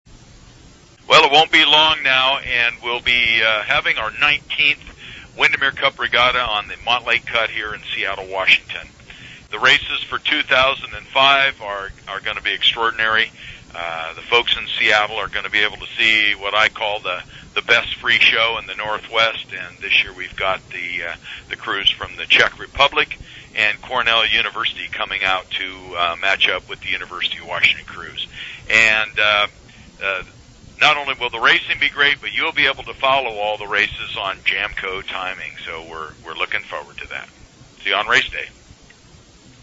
Pre-Race Interviews
Exclusive JAMCO interviews of the coaches competing for the Windermere Cup.